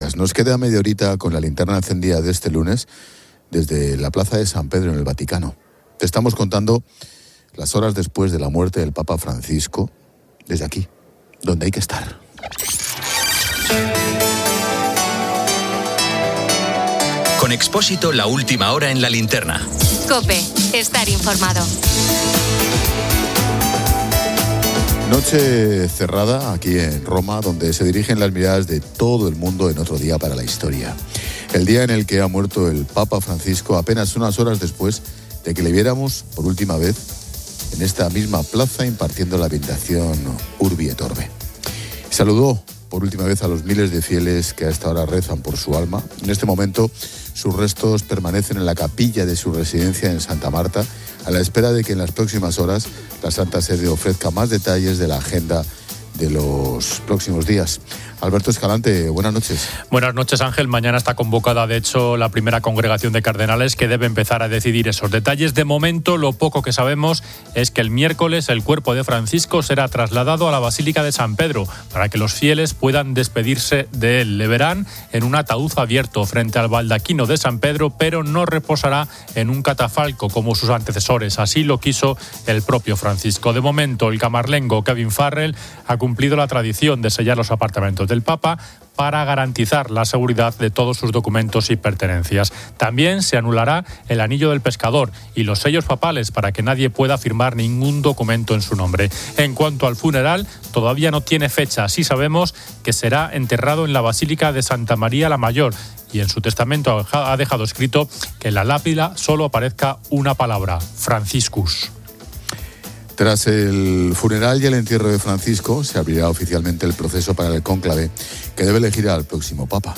Nos queda media horita con la linterna encendida de este lunes desde la Plaza de San Pedro en el Vaticano.